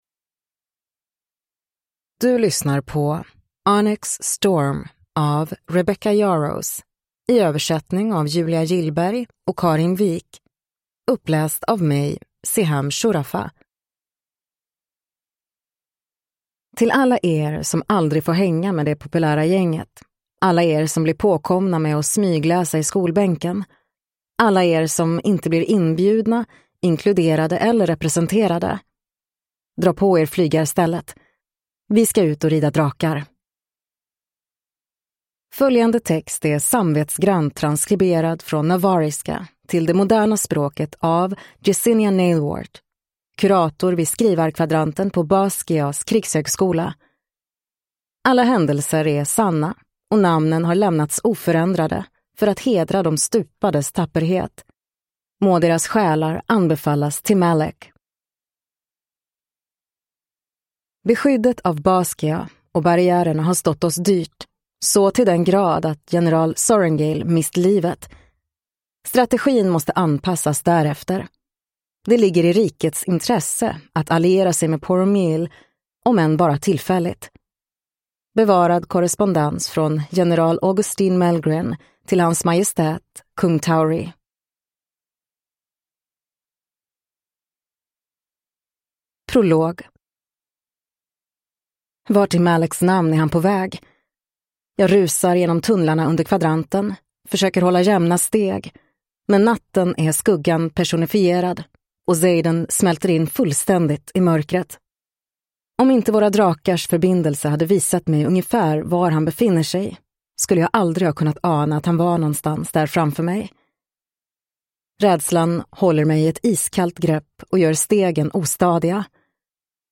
Onyx Storm (svensk utgåva) – Ljudbok